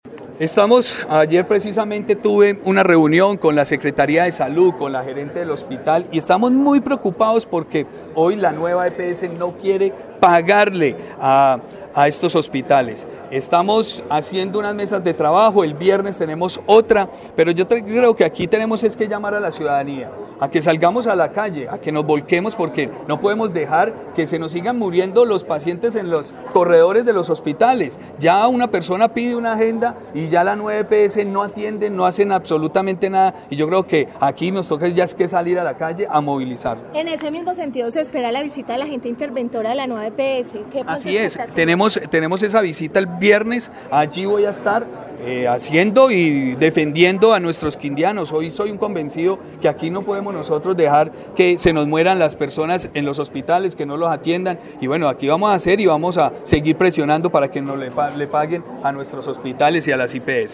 Juan Miguel Galvis, gobernador del Quindío